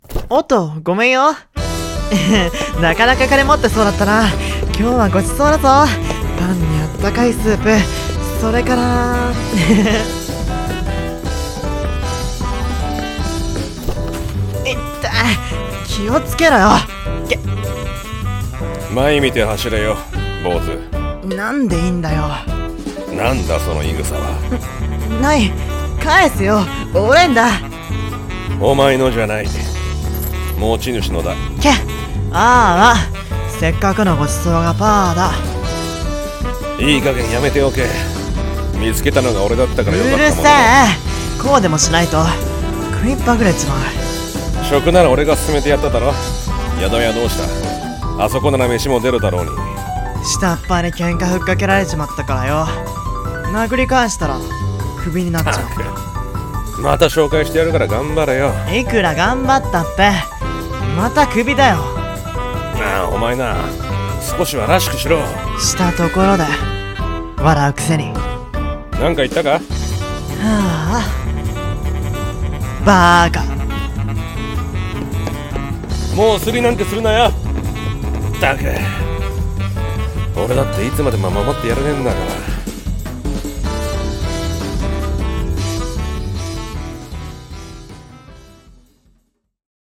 【声劇】今日も今日とて【2人声劇】